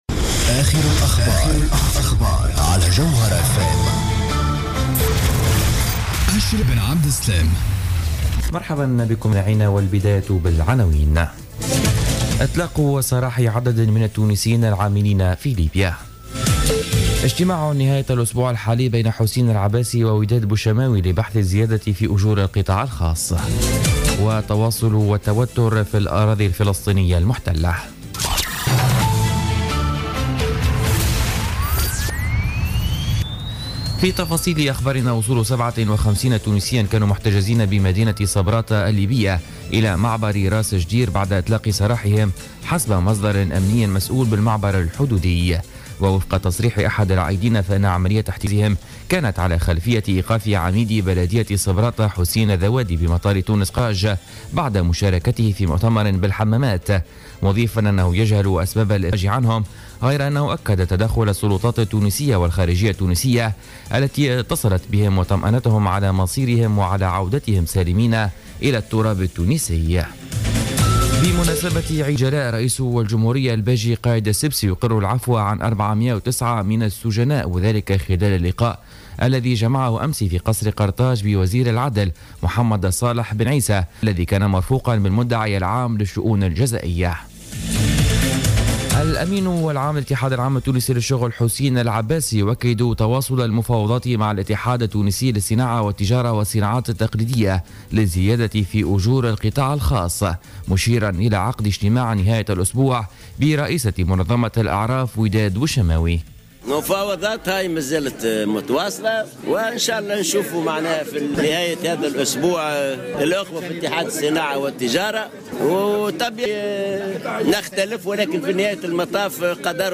نشرة أخبار السابعة صباحا ليوم الأربعاء 14 أكتوبر 2015